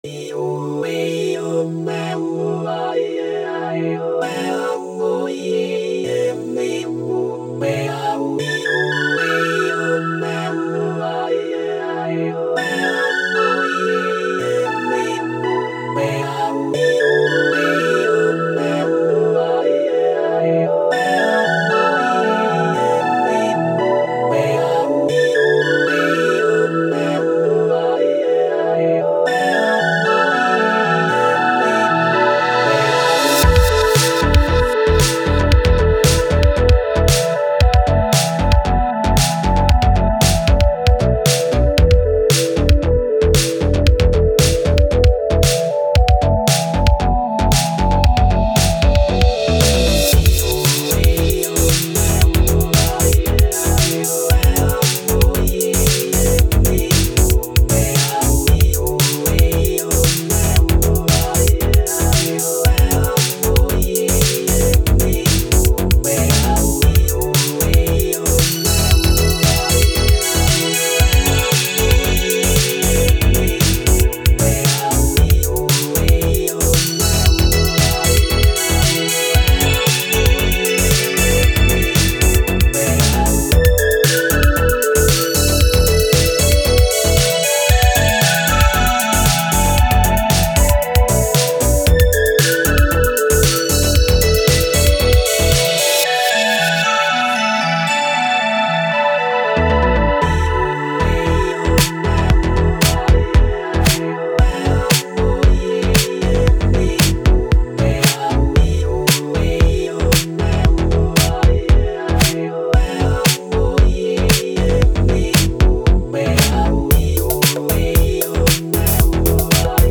Synthwave, Electronic, Dance, Upbeat